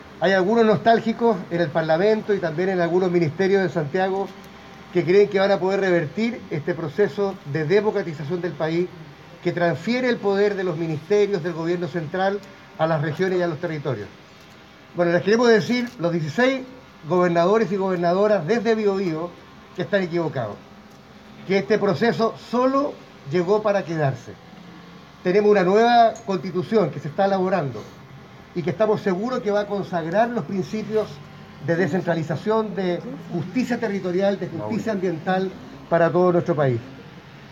Así lo afirmó el Gobernador de la Región Metropolitana, Claudio Orrego, quien recalcó que «este proceso sólo llegó para quedarse, donde el trabajo de una nueva Constitución estamos seguros que va a consagrar los principios de descentralización, de justicia territorial y ambiental para el país”.
encuentro-gobernadores-orrego-5.mp3